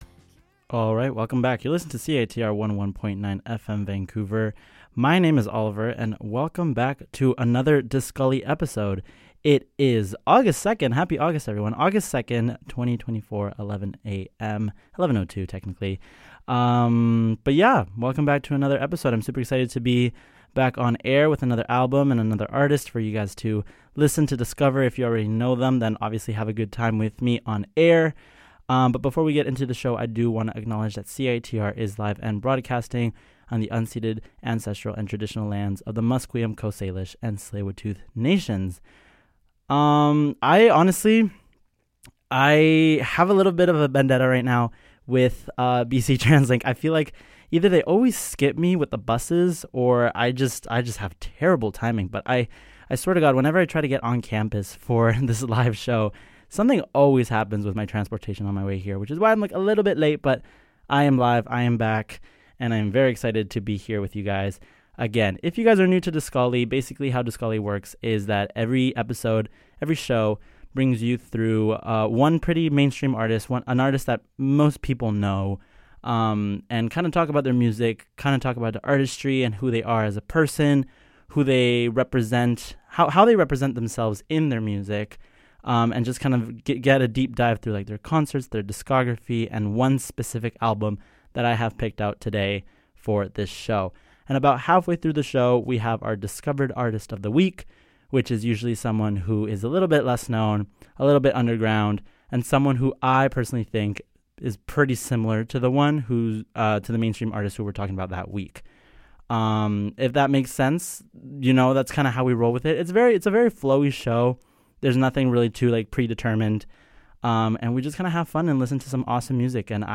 experimental sounds